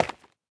Minecraft Version Minecraft Version 1.21.5 Latest Release | Latest Snapshot 1.21.5 / assets / minecraft / sounds / block / dripstone / step1.ogg Compare With Compare With Latest Release | Latest Snapshot
step1.ogg